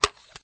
plastic_clack.ogg